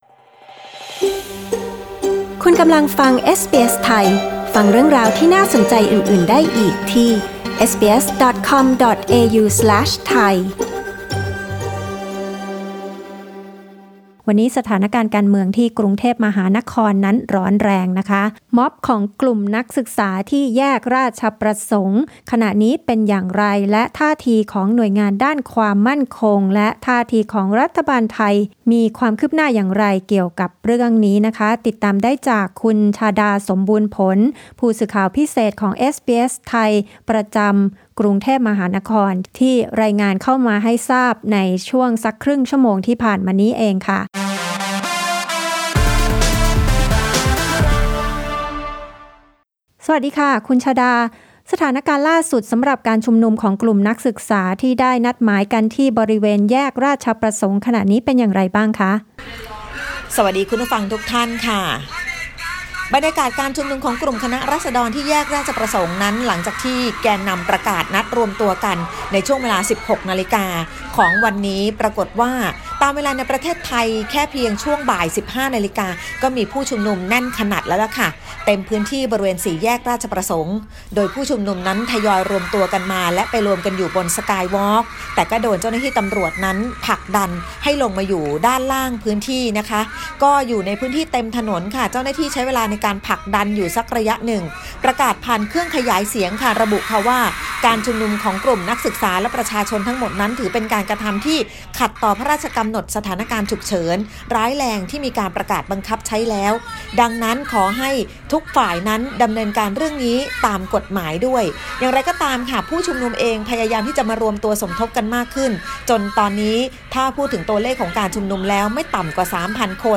ฟังการรายงานสถานการณ์ล่าสุดและเสียงบรรยากาศการชุมนุมมวลชน นำโดยแกนนำนักศึกษาจากแยกราชประสงค์ ที่กำลังเป็นประเด็นร้อนแรง หลังรัฐบาลไทยประกาศ พรก.ฉุกเฉินร้ายแรงในพื้นที่กรุงเทพฯ
ฟังรายงานสถานการณ์ล่าสุดจากสถานที่ชุมนุม